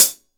Index of /90_sSampleCDs/AKAI S6000 CD-ROM - Volume 3/Drum_Kit/DRY_KIT1